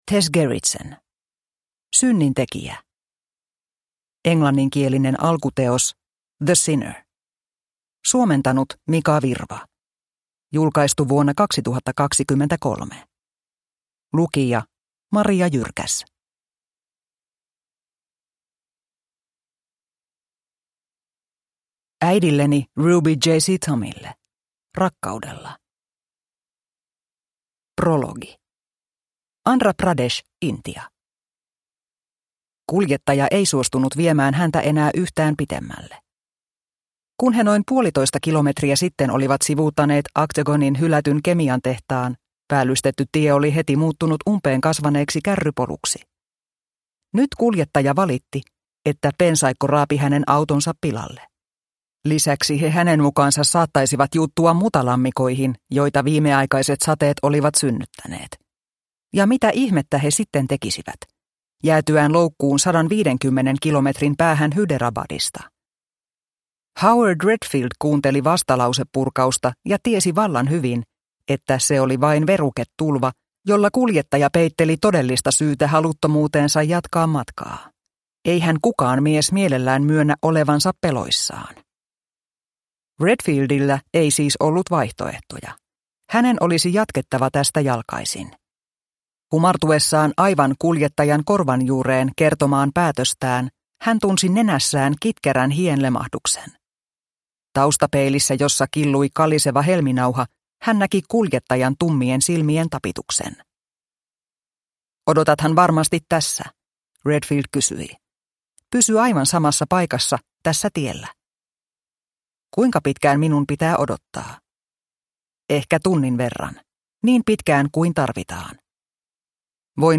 Synnintekijä – Ljudbok – Laddas ner